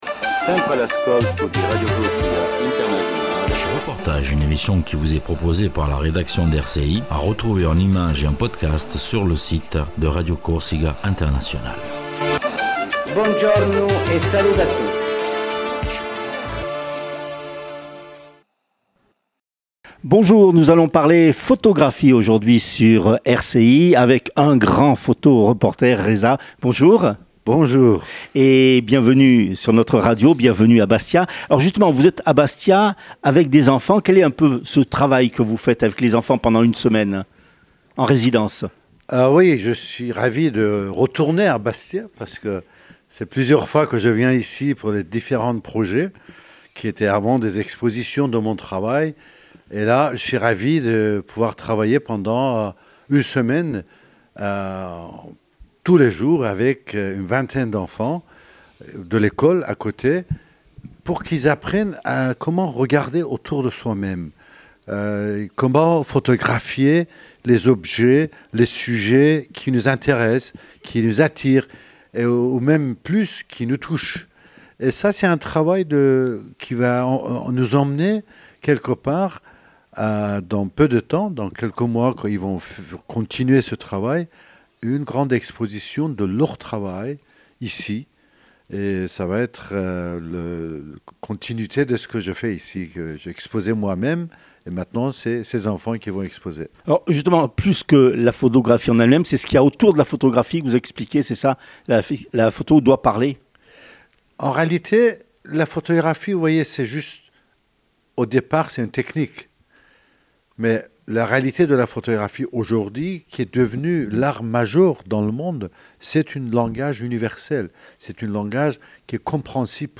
REPORTAGE SUR LE PHOTOREPORTER REZA DEGHATI